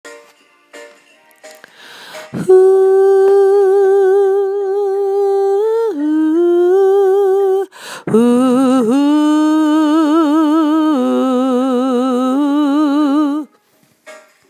download OEH-tjes